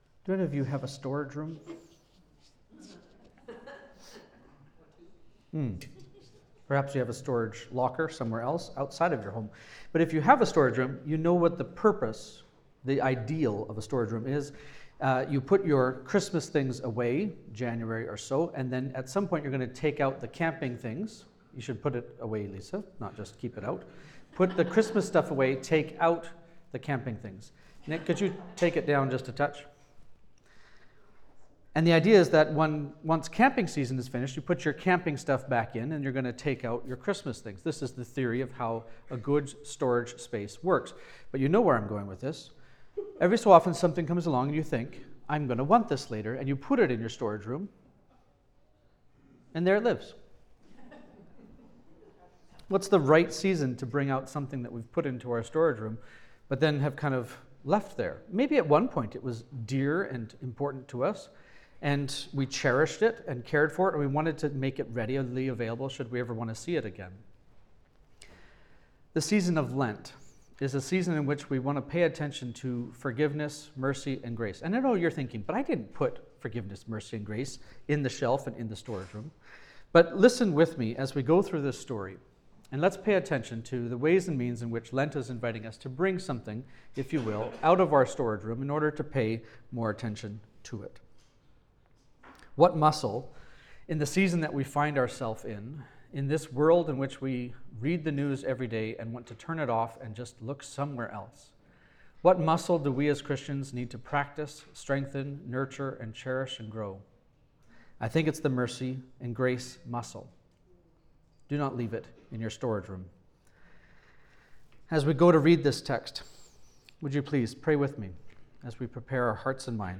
Sermons | Living Hope Church